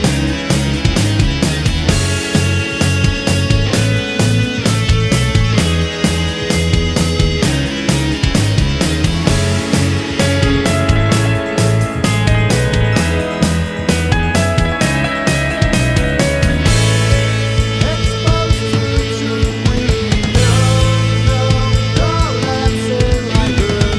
no Backing Vocals Indie / Alternative 4:04 Buy £1.50